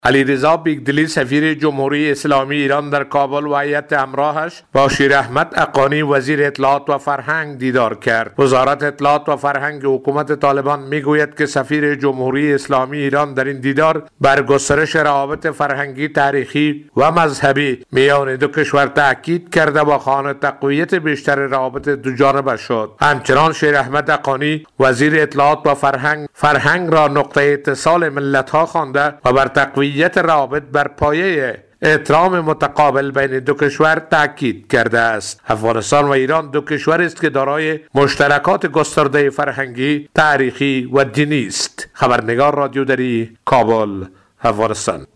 گزارش فرهنگی